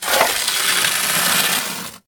hand mining